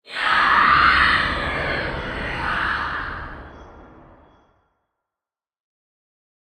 shriek1.ogg